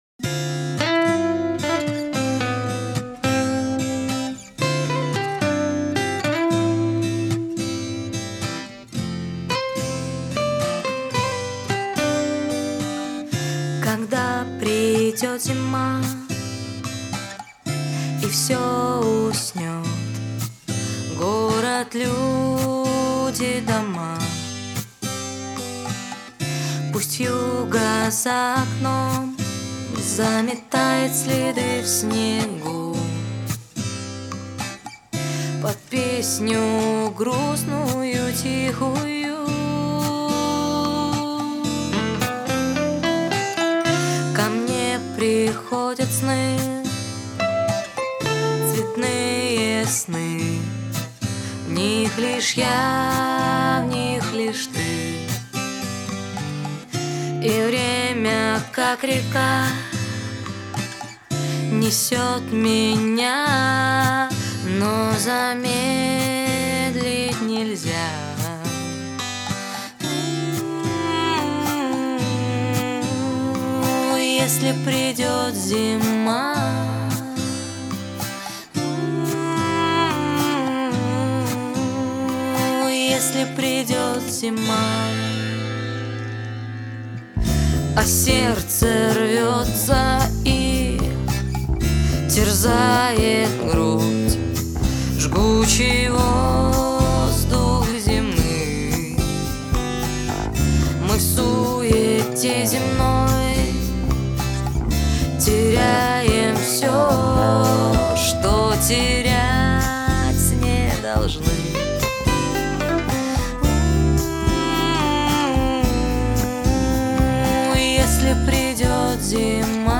есть акустические композиции